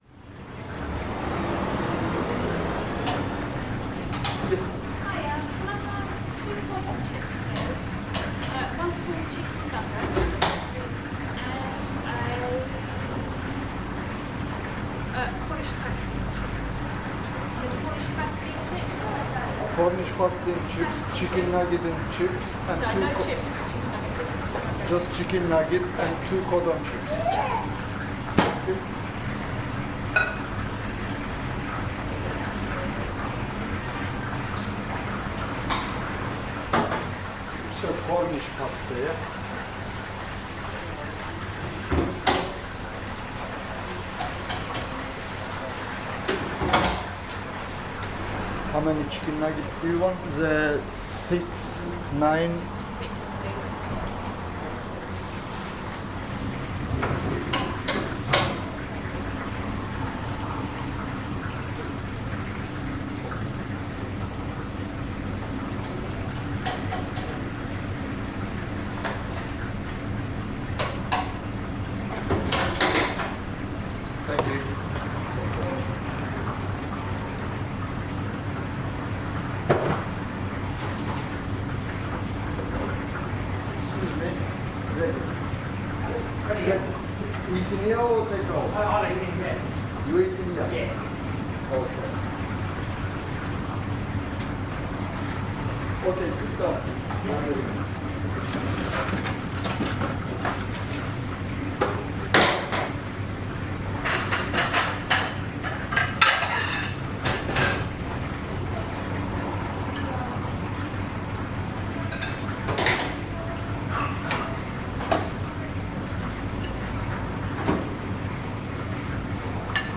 Chips cook, orders are placed, dinner is served on Margate seafront.
Part of the Cities and Memory Margate sound map for Dreamland.